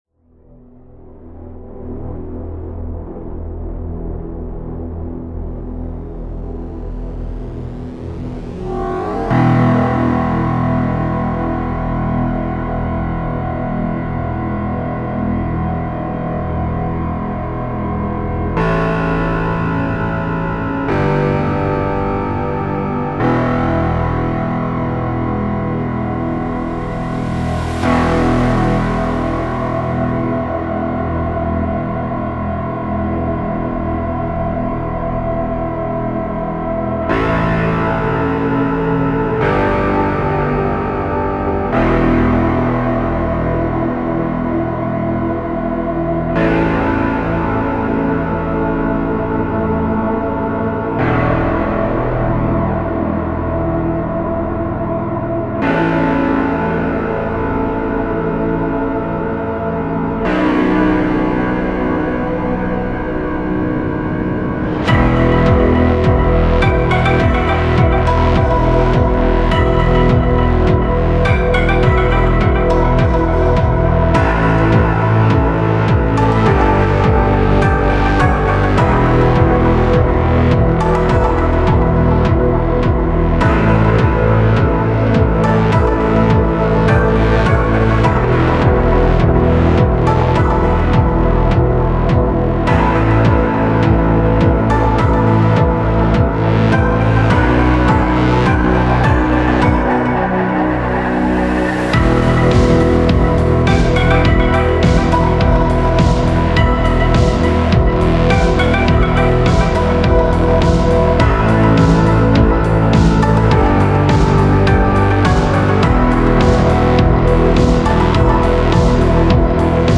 As always: space vibes, industrial, techno little bit :D
Space vibes ...